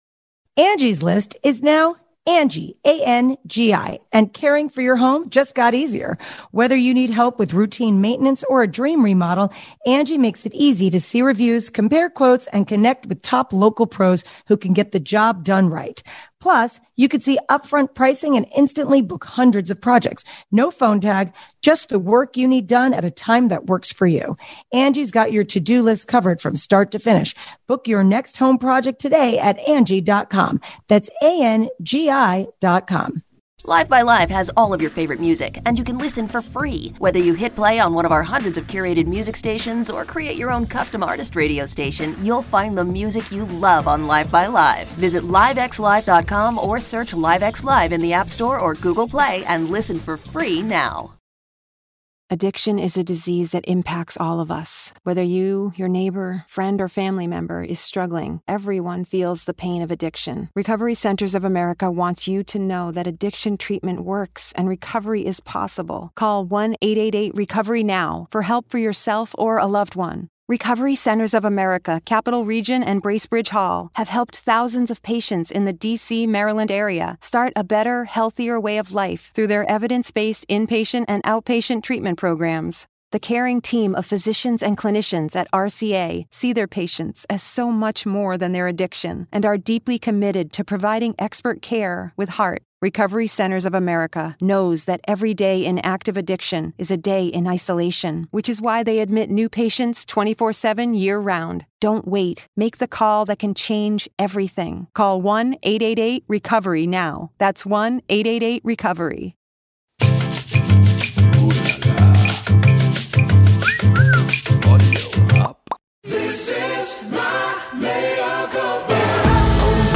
The Lincoln Project's Rick Wilson joins Michael to discuss how we go about dismantling Trump's ugly legacy and hold the rioters and those who supported them accountable.